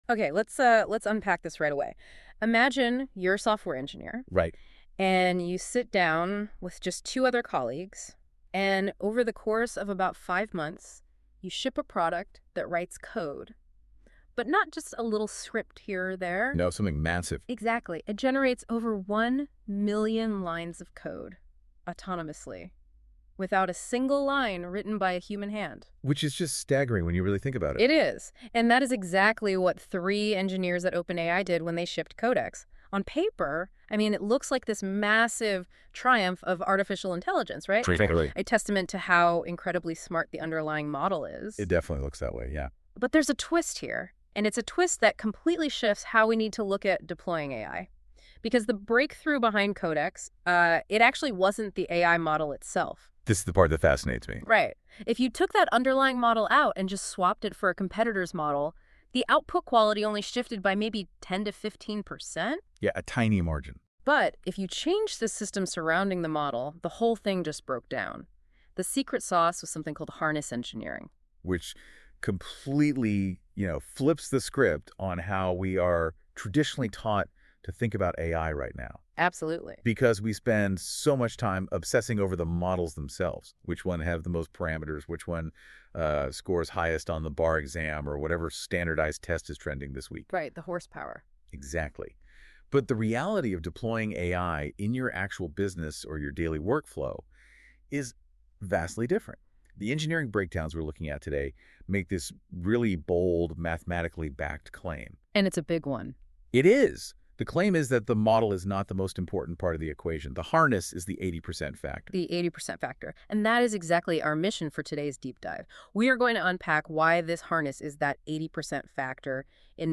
Two AI hosts discuss what harness engineering is, why it matters more than the model, and how companies like Vercel and LangChain are applying it — in a 24-minute deep dive.
what-is-harness-engineering-audio-overview.mp3